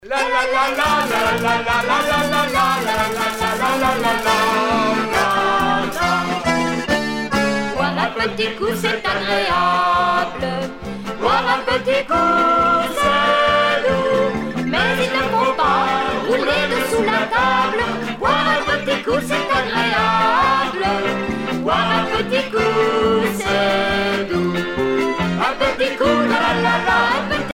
circonstance : bachique
Pièce musicale éditée